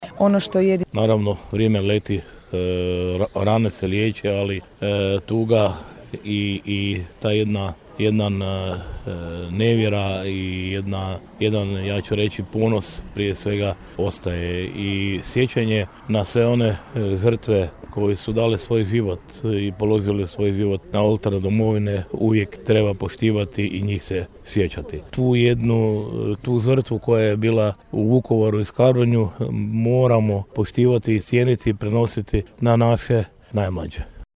Gradonačelnik Ljubomir Kolarek: